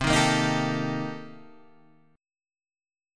Cm13 chord {8 6 8 8 8 5} chord